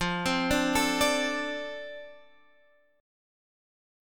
F6 Chord
Listen to F6 strummed